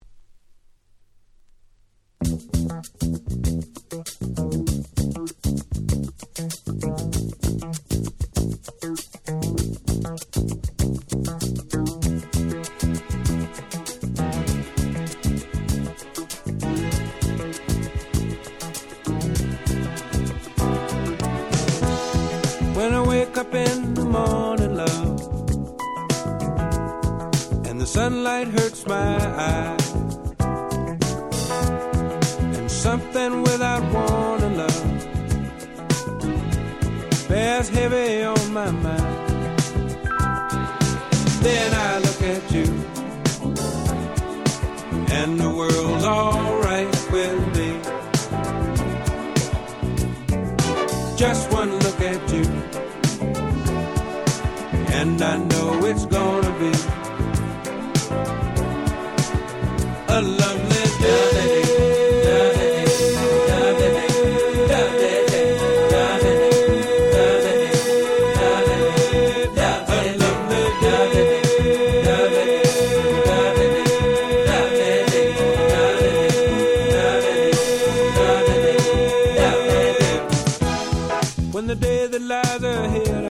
音質もバッチリ！！
Soul ソウル レアグルーヴ